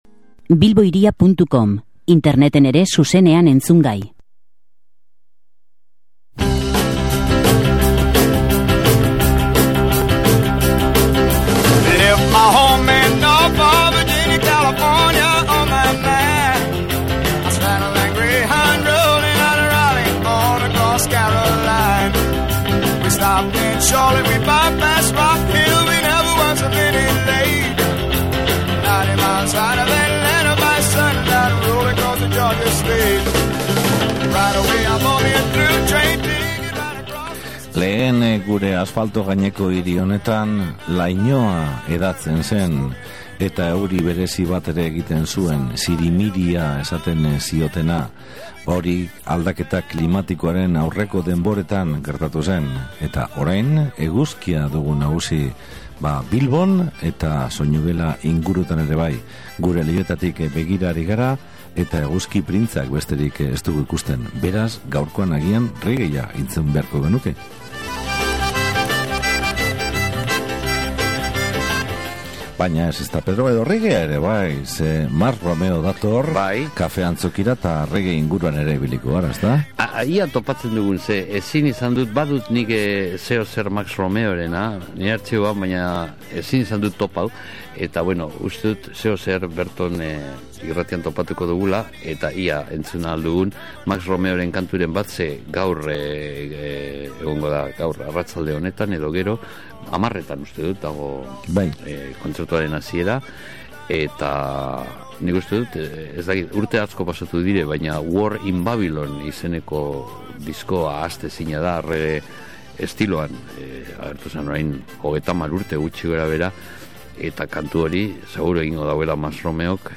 Marching Band
AEBetako rock independentea
Asturiasko folk laukotea